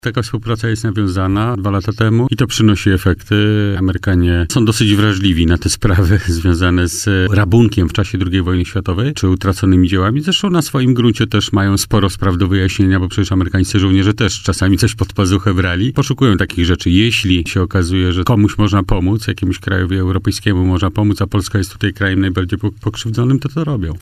Wiceminister resortu – Jarosław Sellin przyznał w poranku „Siódma9” na antenie Radia Warszawa, że na współpracy polsko-amerykańskiej w poszukiwaniu zaginionych dzieł sztuki korzystają obie strony.